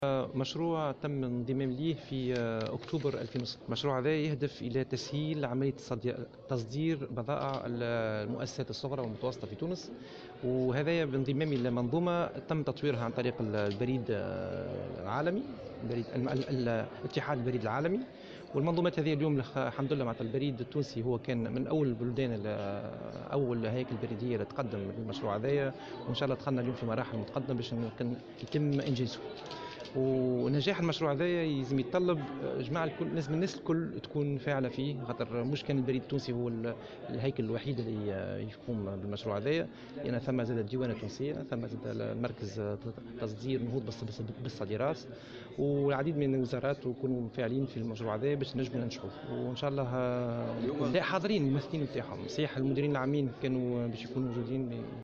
La Poste tunisienne a annoncé vendredi 27 janvier lors d'une conférence de presse tenue à Tunis, le lancement de son projet pilote intitulé "Easy Export", développé en coopération avec l'Union postale universelle.